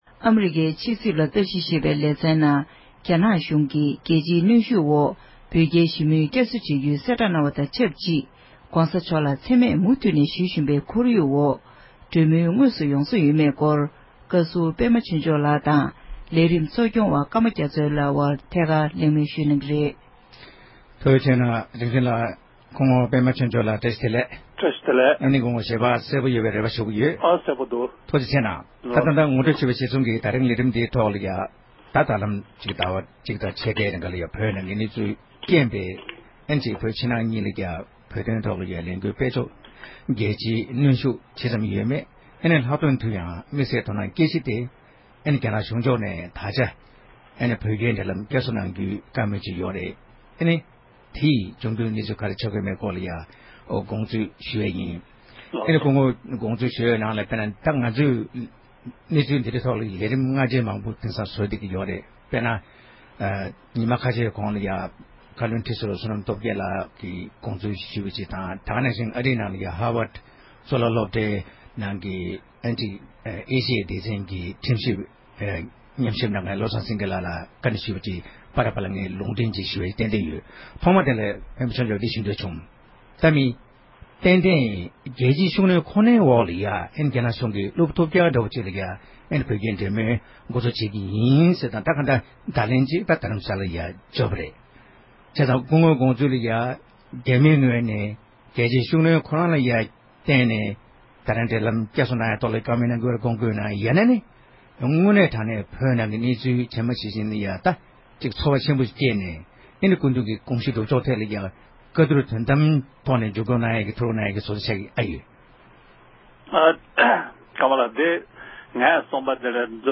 བགྲོ་གླེང་གནང་བ་ཞིག་གསན་རོགས་གནང༌༎